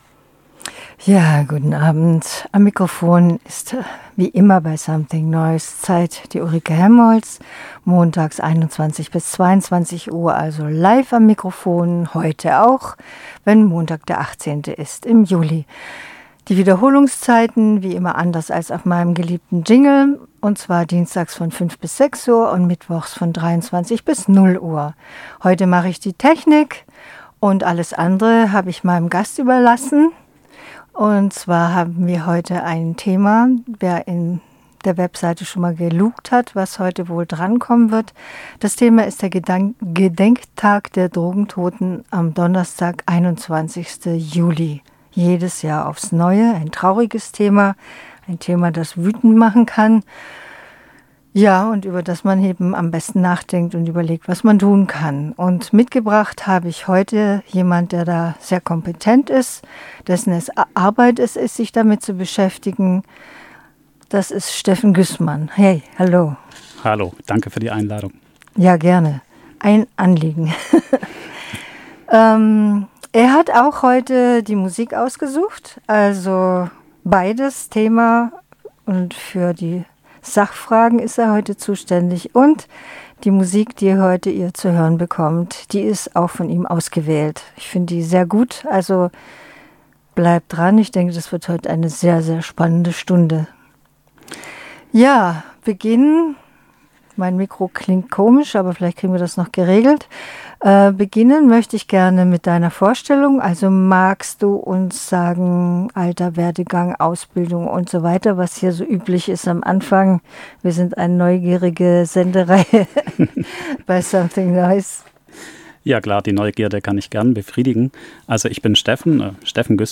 Umrissen wird in dieser Stunde das Thema Sucht, verschiedene Sichtweisen auf Drogenkonsum und die Möglichkeiten der Unterstützung von Usern, sowie Folgen der gegenwärtigen Drogenpolitik. Die Musik dieser Stunde wurde vom Gast ausgesucht und bewegt sich von wavigen Pop über Titel mit noisigen, leicht psychedelischen Analog-Synthie-Beats zu kurzen, erzählerischen Songs, bezogen auf das Thema.